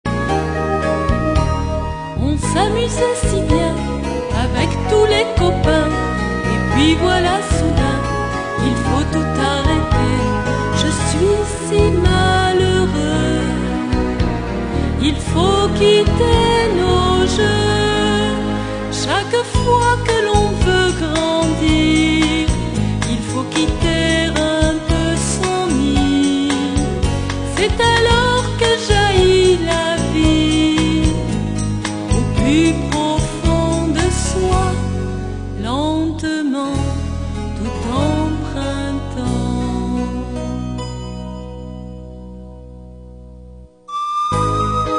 13 chants pour l'éveil à la Foi des plus jeunes.